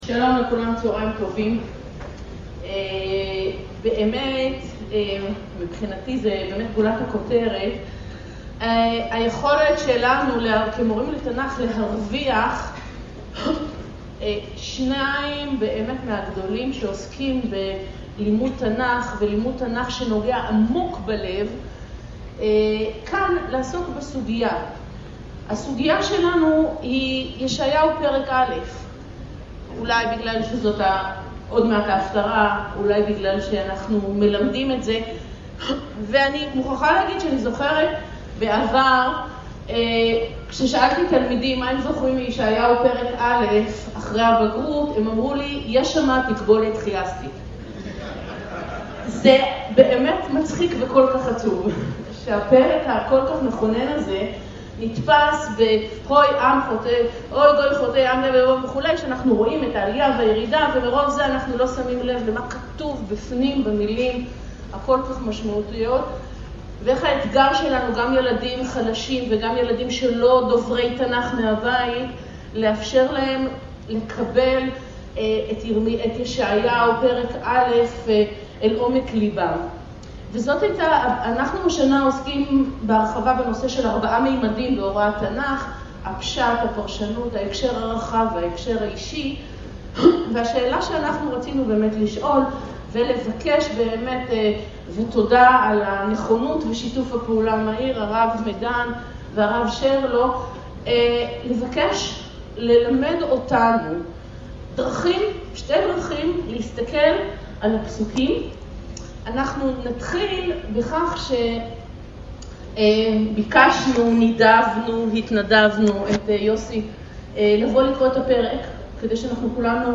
השיעור באדיבות אתר התנ"ך וניתן במסגרת ימי העיון בתנ"ך של המכללה האקדמית הרצוג תשע"ה